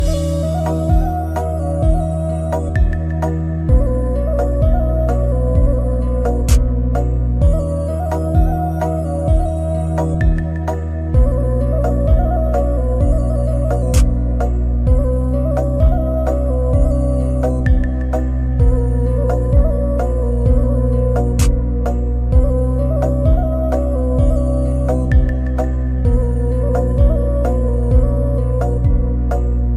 Beutiful lonely ringtone.